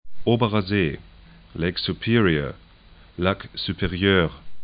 'o:bərɐ 'ze: